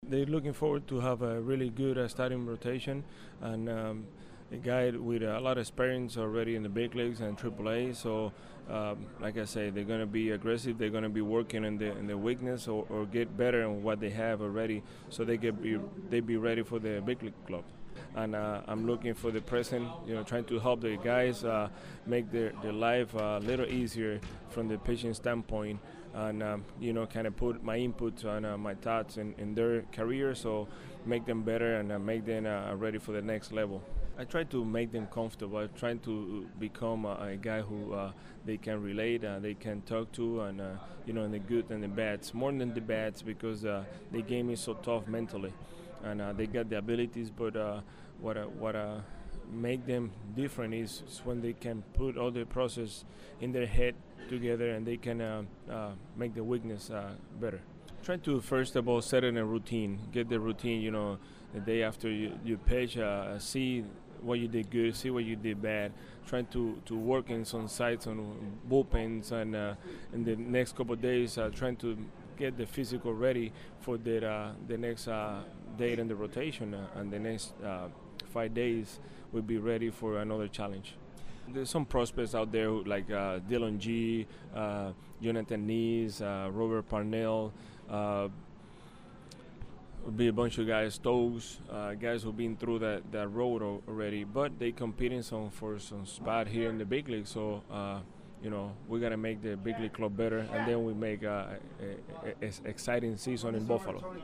I took the liberty to break some of the conversations down and give you the meat and potatoes:
Buffalo Pitching Coach – Ricky Bones CLICK HERE!